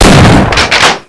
weapons
shotgun_npc.wav